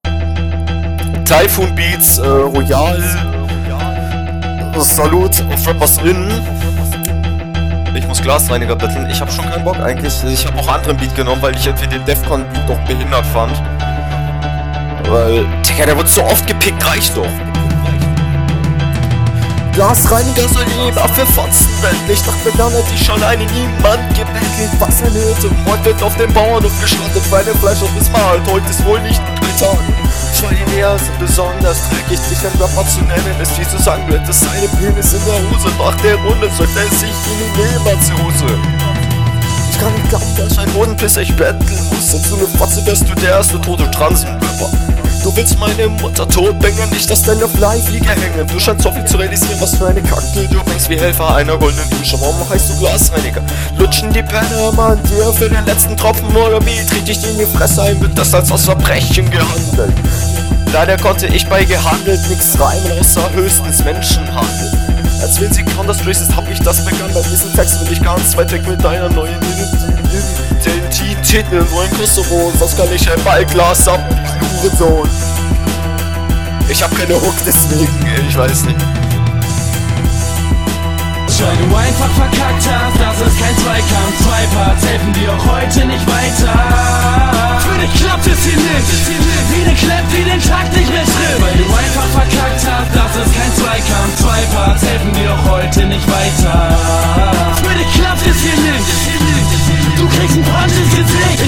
kann fast nix verstehen bruder beat muss leiser
Das ist ganz schlecht gemischt, Die Stimme geht komplett unter dem Beat unter.